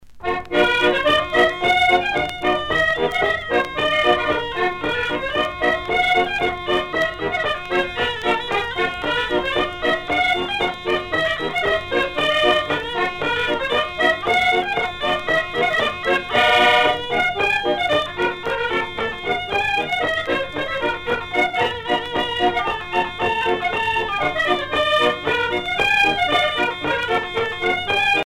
danse : polka des bébés ou badoise
Pièce musicale éditée